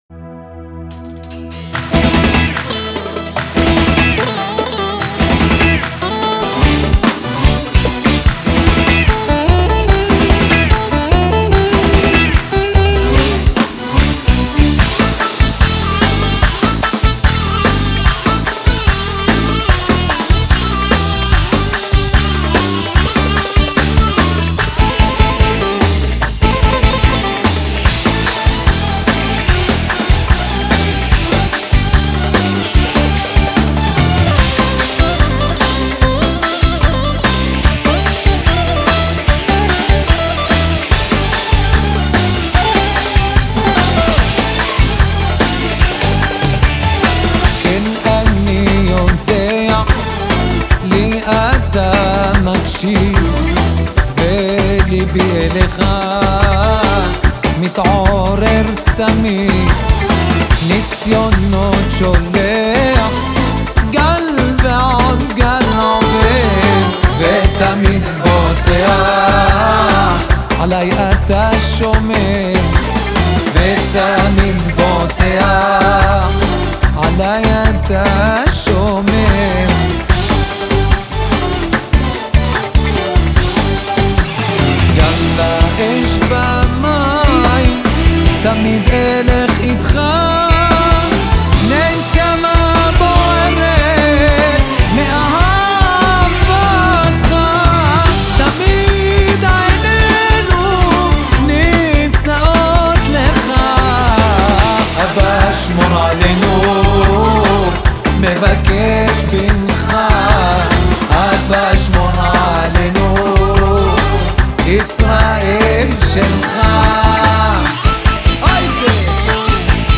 הלחן טורקי
תופים
גיטרות בגלמה
קלידים